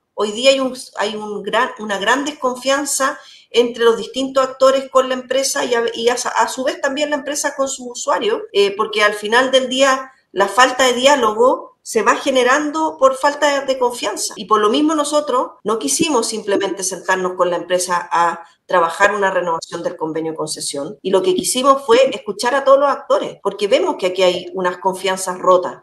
La subsecretaria de Hacienda, Heidi Berner, explicó en conversación con Radio Paulina que el Ministerio de Hacienda está trabajando en la renovación del convenio con ZOFRI S.A., pero con nuevas condiciones que permitan mayor transparencia en la administración y gestión de la zona franca.